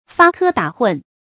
發科打諢 注音： ㄈㄚ ㄎㄜ ㄉㄚˇ ㄏㄨㄣˋ 讀音讀法： 意思解釋： 以滑稽的動作和語言引人發笑。